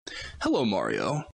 hello-mario-made-with-Voicemod-technology.mp3